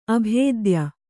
♪ abhēdya